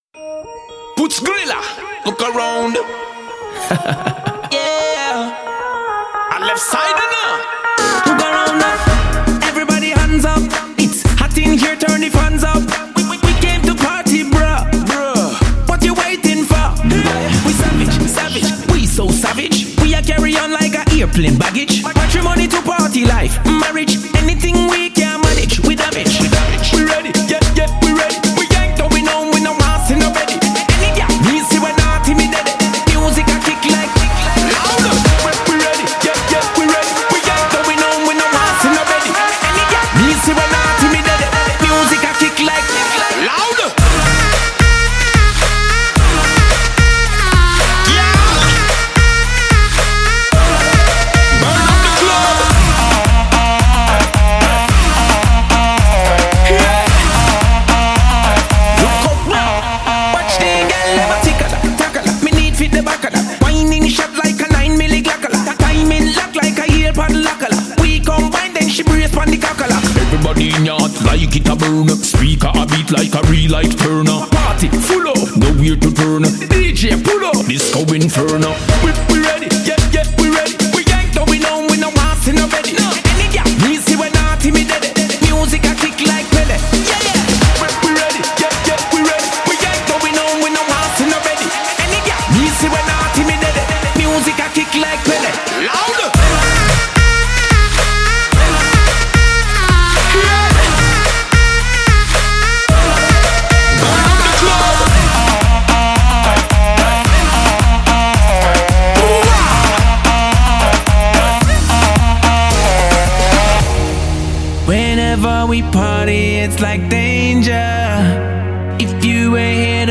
ریمیکس
Download shuffle dance music